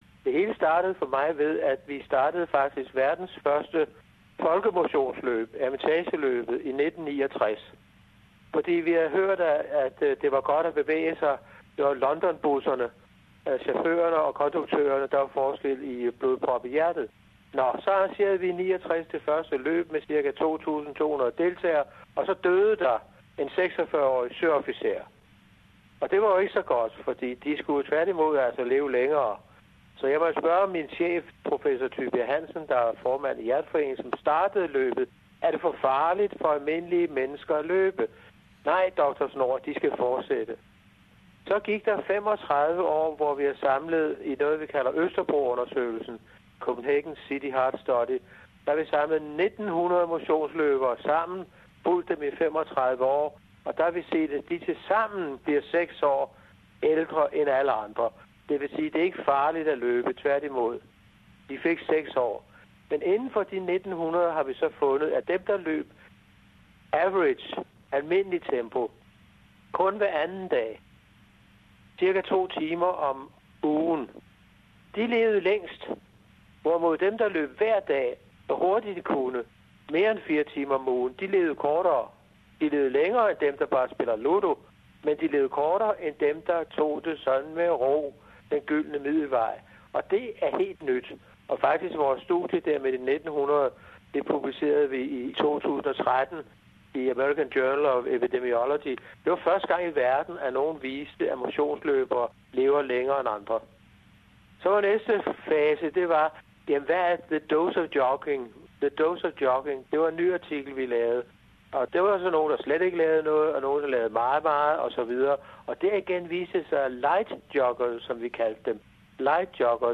I dette interview fortæller han, hvad det er for nogle motions-undersøgelser, de har lavet - og hvordan det hele begyndte.